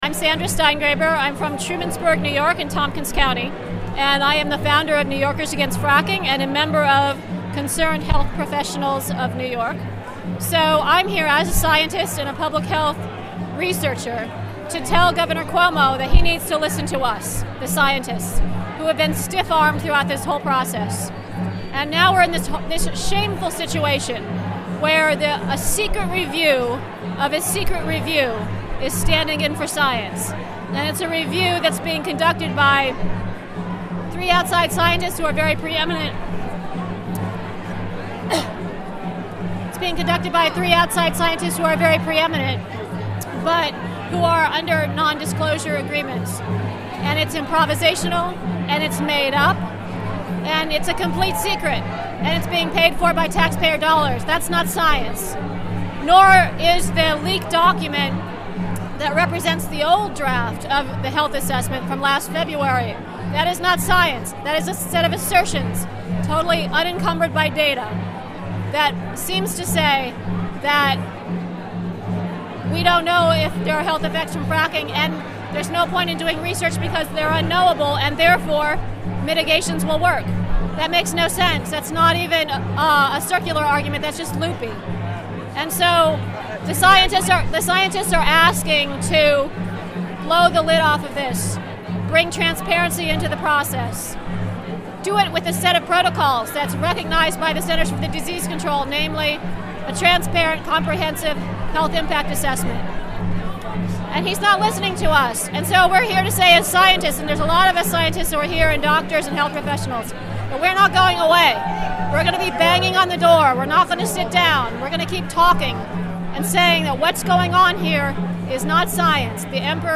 at the Anti-Fracking Rally prior to Gov. Andrew M. Cuomo's State of the State Address.
Interviewed